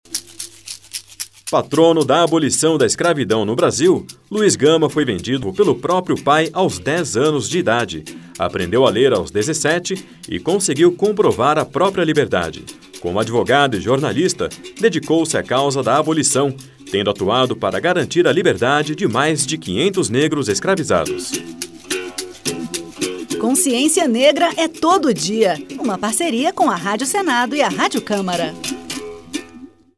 A Rádio Senado e a Rádio Câmara lançam uma série de cinco spots que homenageiam personalidades negras que marcaram a história do Brasil, como Abdias Nascimento, Carolina Maria de Jesus, Dragão do Mar, Maria Firmina dos Reis e Luiz Gama.